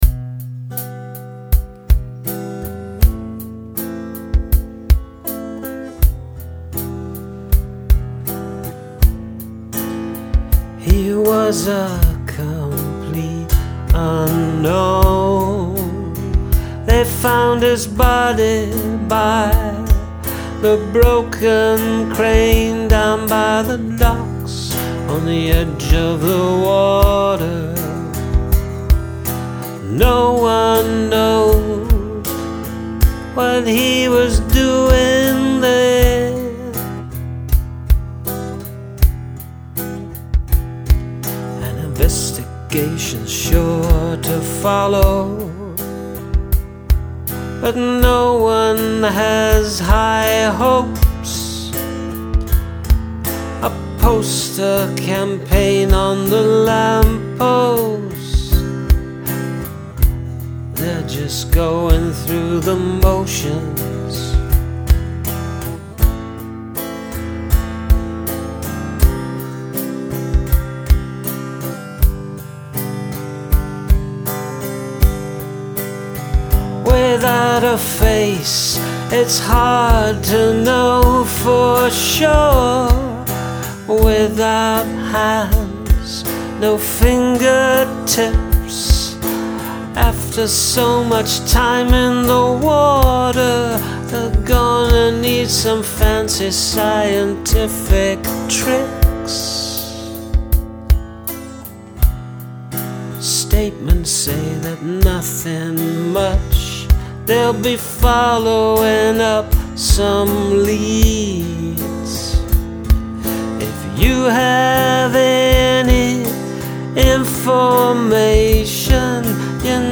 Good sounding track that supports the dark lyric.
Mean, moody , magnificent!
It has a Western or Tarantino movie vibe.